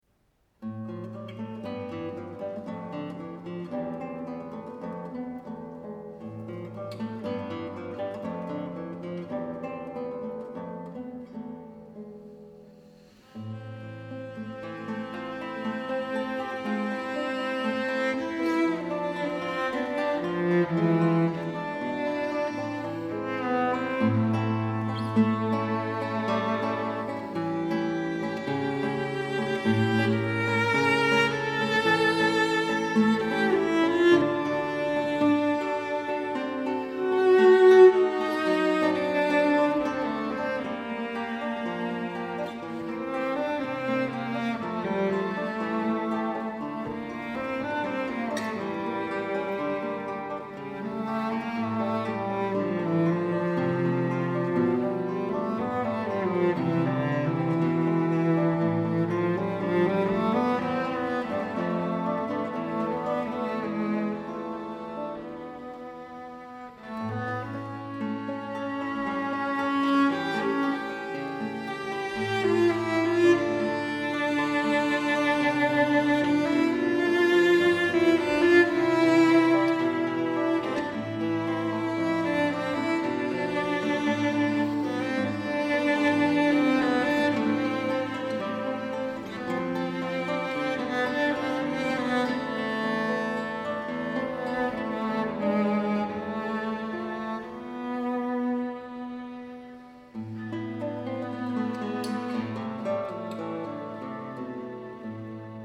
★婉轉的大提琴、錚鏦流麗的吉他，絕美饗宴！